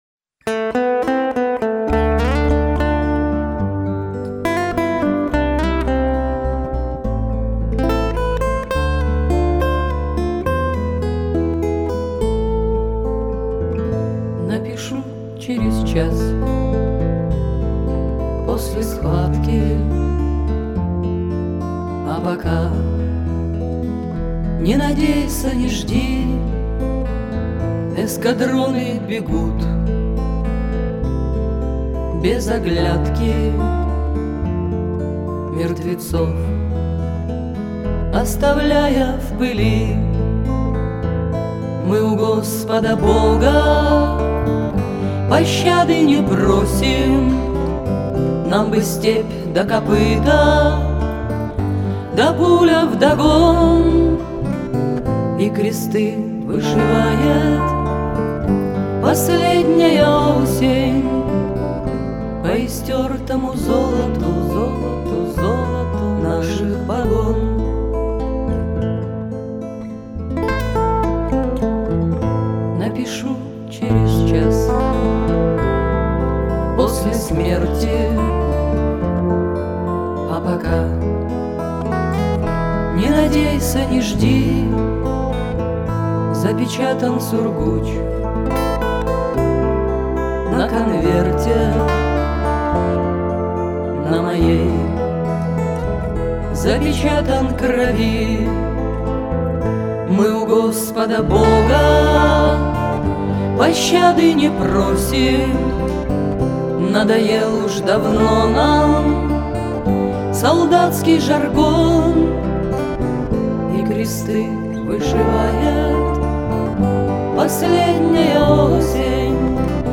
Еще одно исполнение у себя нашла
Правда, я как-то не могу воспринять это в дамском исполнении.